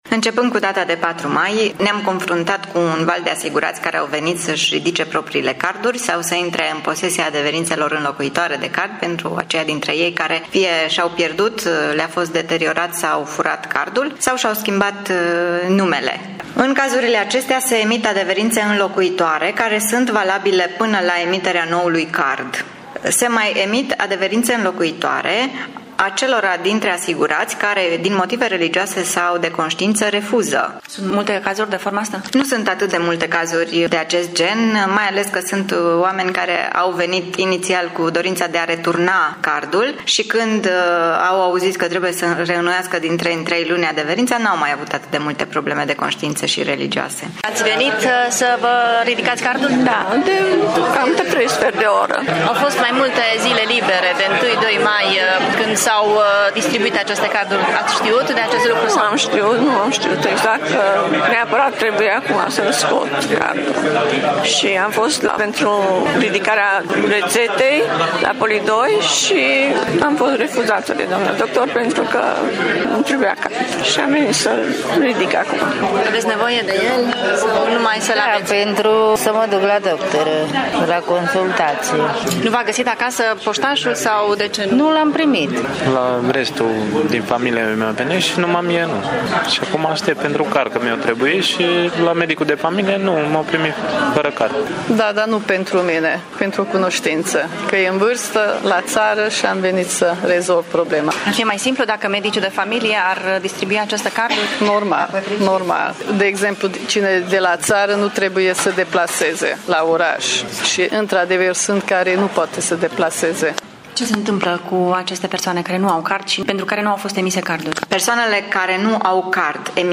reportaj